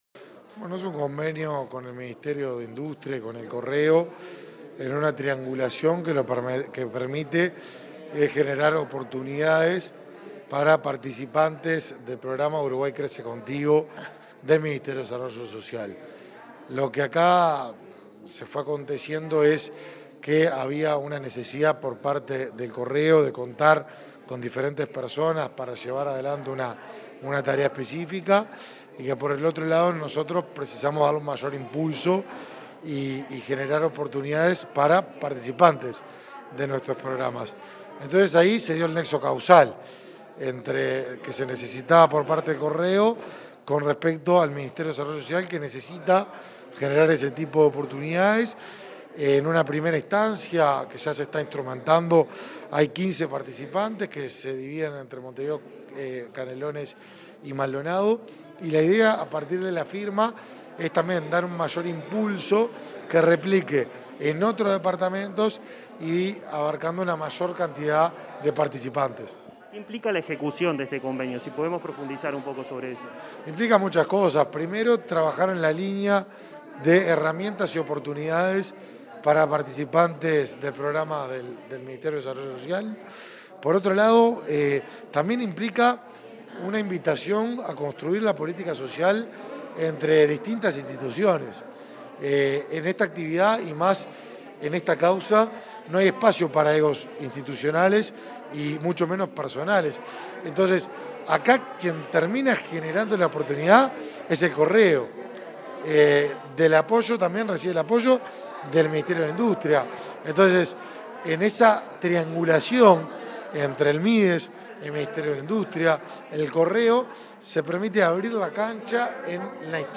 Entrevista al ministro de Desarrollo Social, Martín Lema